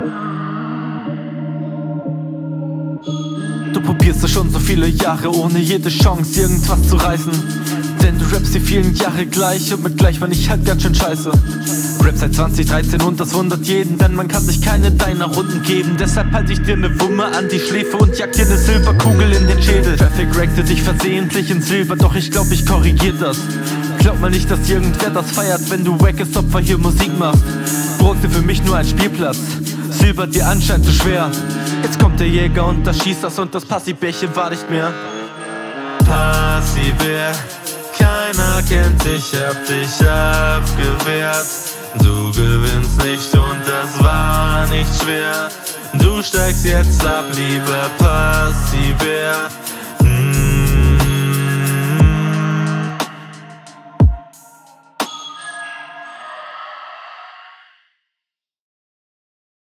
Ziemlich gut gerappt auf diesen eher ungewöhnlichen Beat, Reimtechnik und Gegnerbezug wurden auch zufriedenstellend erfüllt.
Schöne Atmo in der Runde.
Flow stellenweise cool, mir fehlts hier aber an anständigen Punchlines/Pointen.